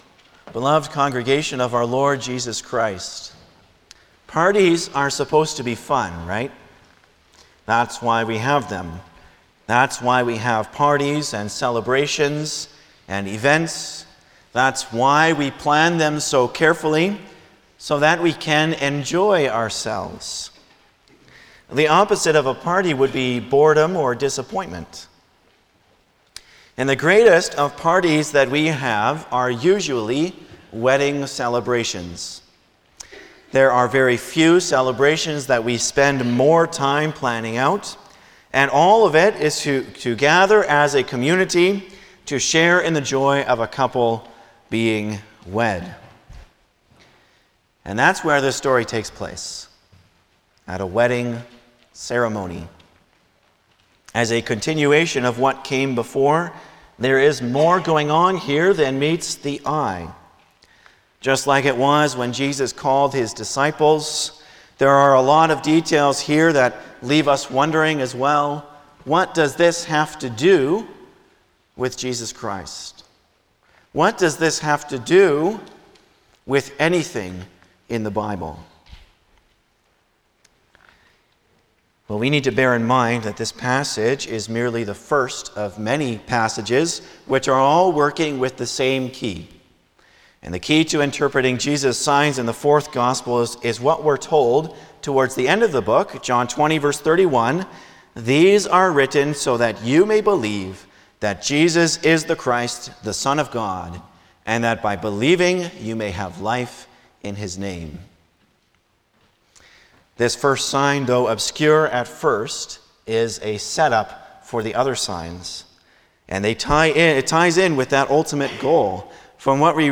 Passage: John 2:1-11 Service Type: Sunday morning
09-Sermon.mp3